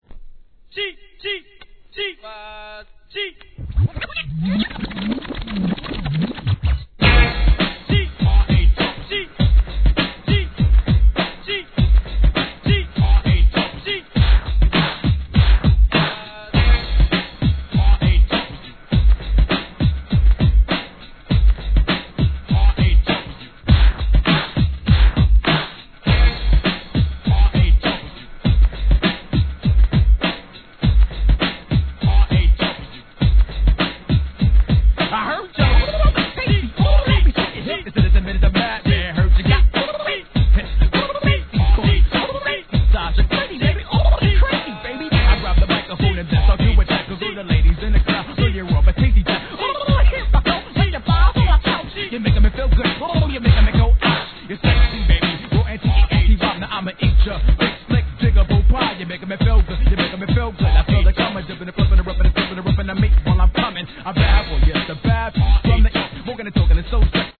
完全HIP HOP作